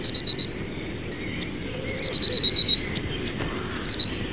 bluetit.mp3